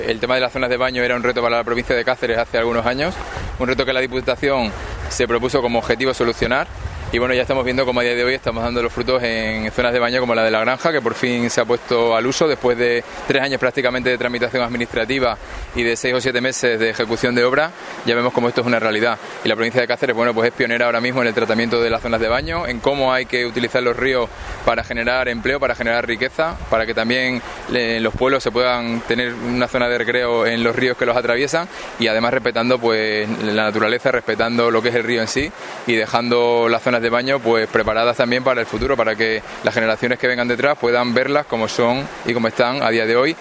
CORTES DE VOZ
Luis Fernando García Nicolás 2-Vicepresidente tercero de Fomento, Movilidad y Agenda Provincial 1